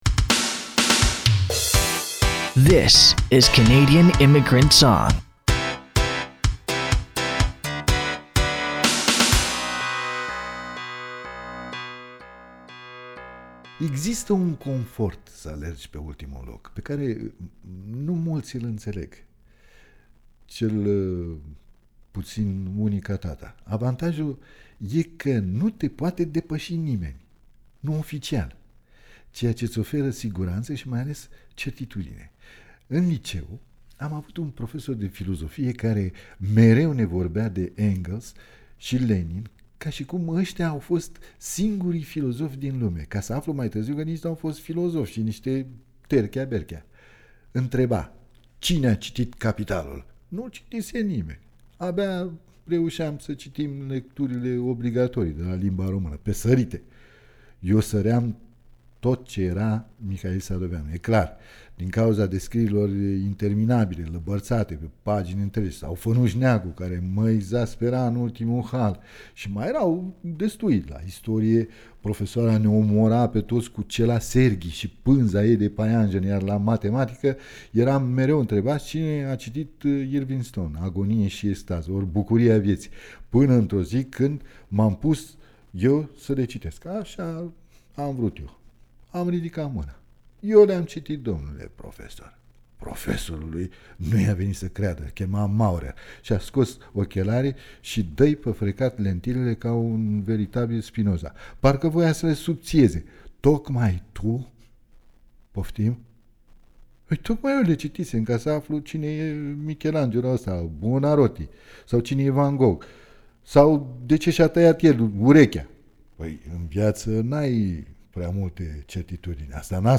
lectura